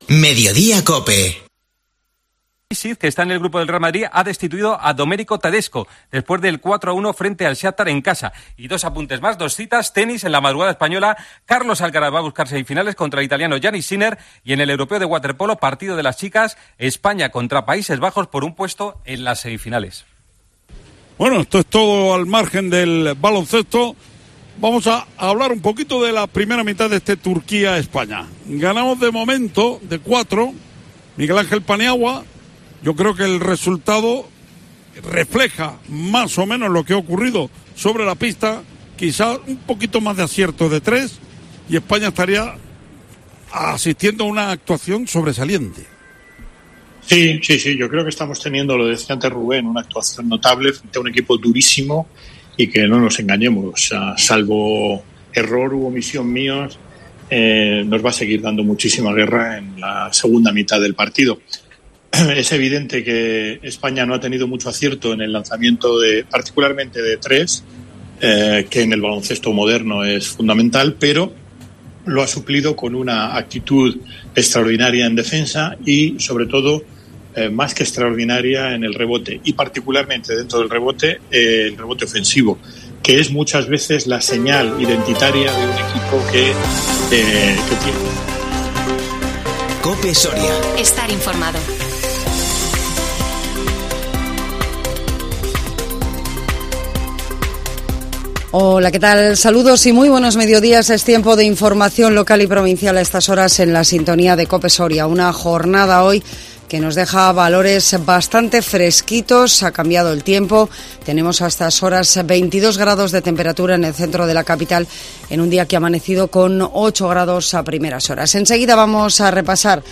INFORMATIVO MEDIODÍA COPE SORIA 7 SEPTIEMBRE 2022